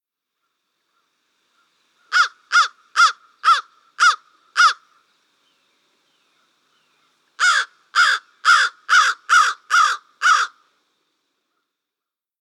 American Crow
How they sound: These birds are well known for the sound they often make: a series of loud, harsh caws.
American_Crow_1_Calls.mp3